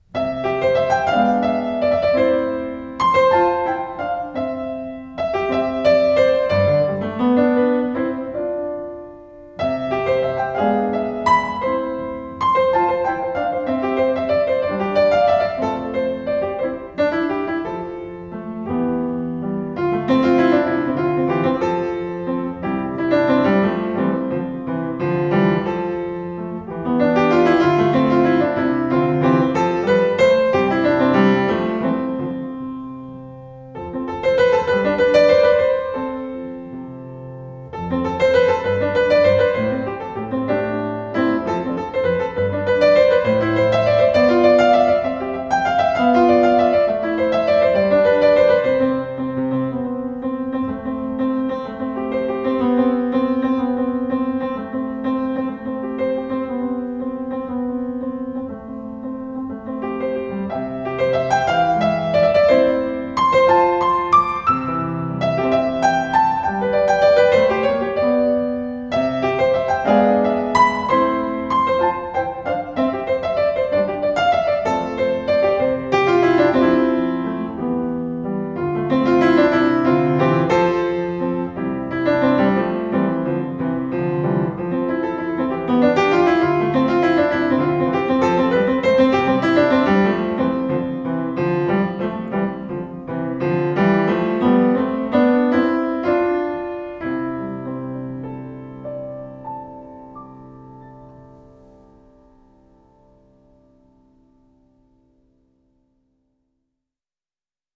solo on acoustic Steinway model D concert grand piano.